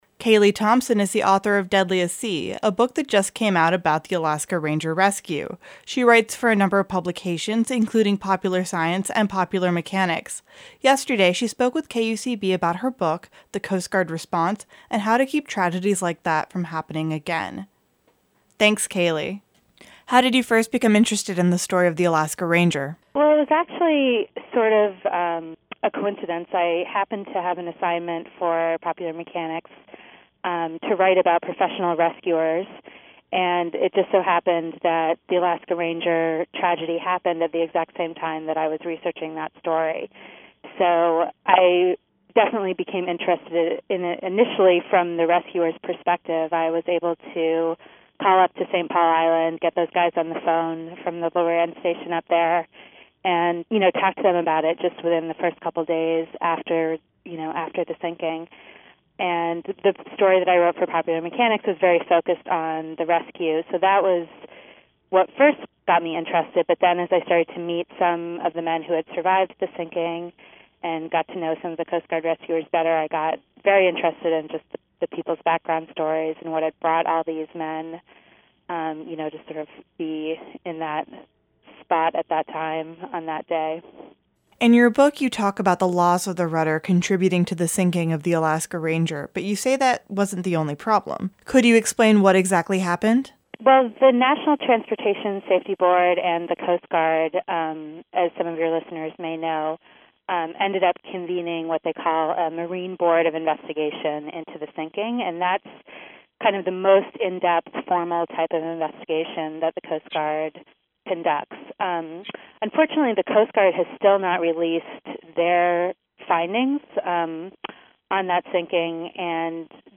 She talks with KUCB about the her book, the Coast Guard rescue, and how to keep tragedies like that from happening again.